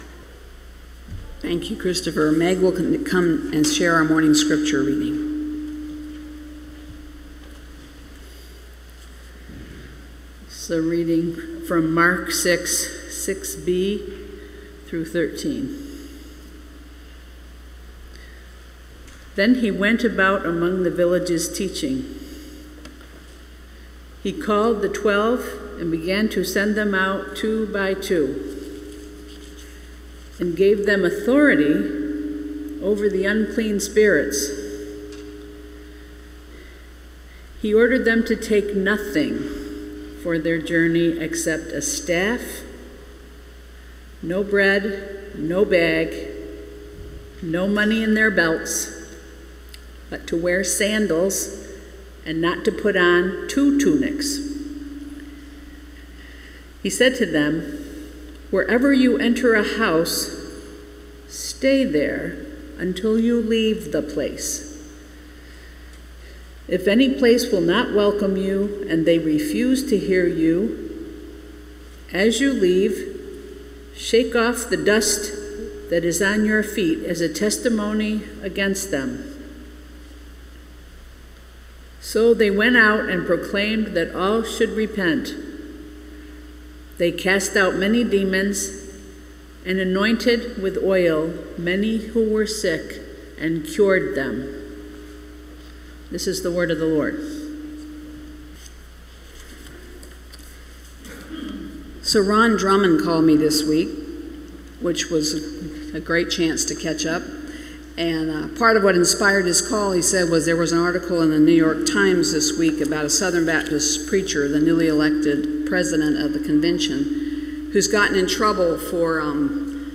Recorded Sermons - The First Baptist Church In Ithaca